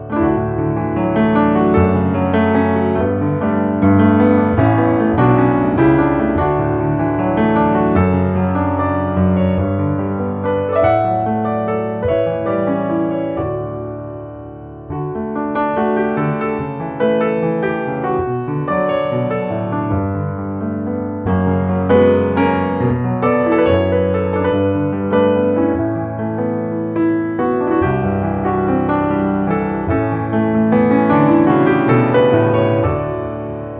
14 original, easy listening piano solos.